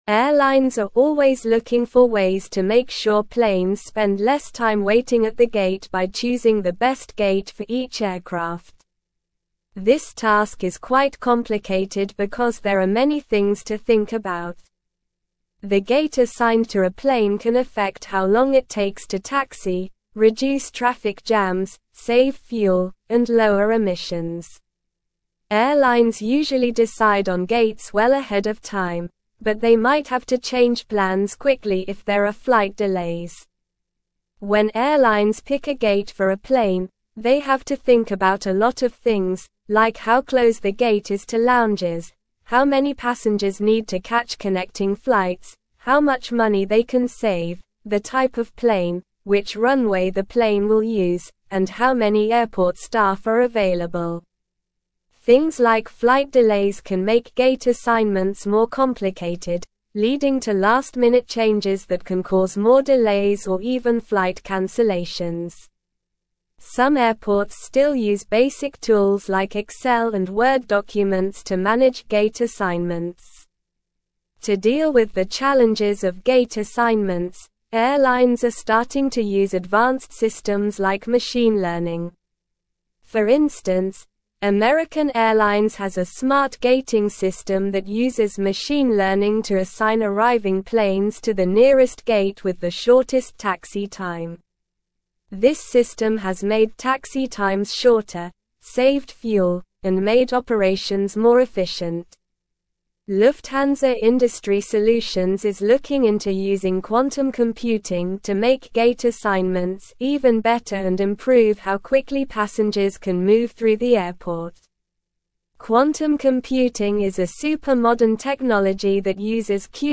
Slow
English-Newsroom-Upper-Intermediate-SLOW-Reading-Efficient-Gate-Allocation-in-Airports-Innovations-and-Benefits.mp3